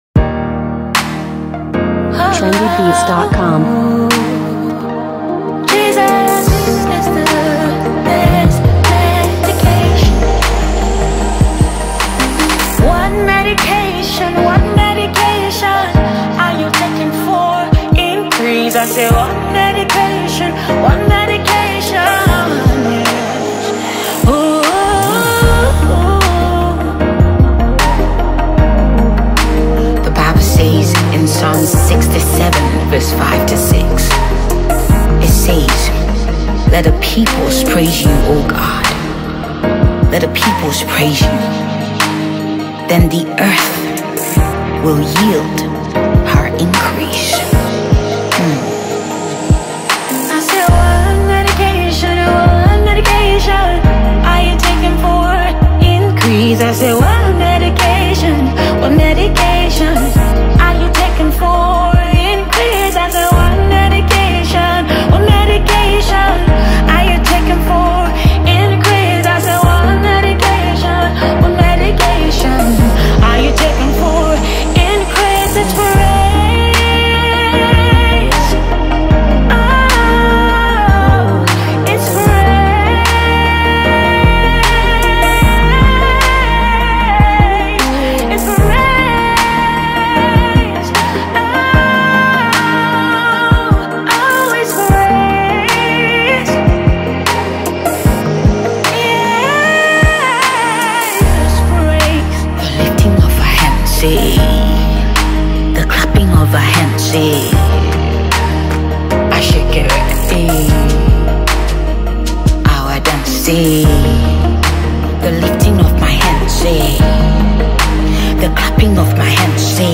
sonorous vocals